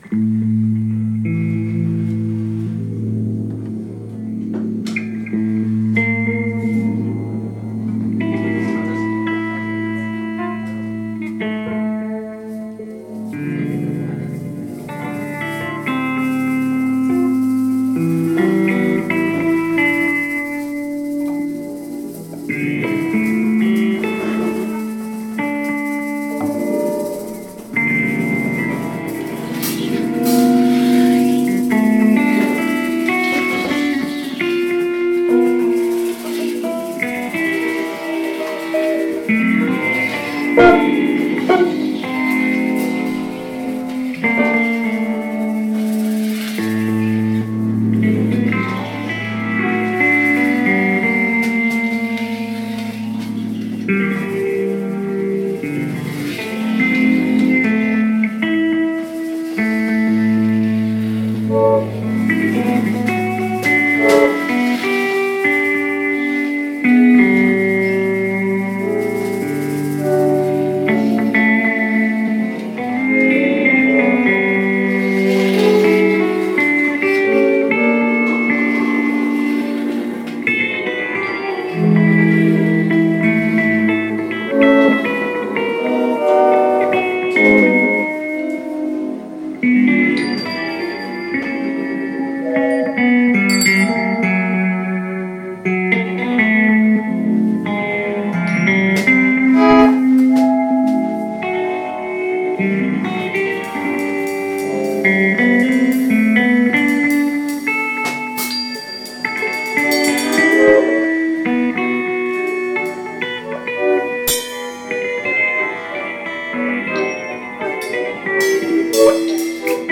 Saxophone/Bassklarinette/Percussion
E-Gitarren
Schlagzeug
Live aufgenommen bei der Soester Jazznacht 07.02.2026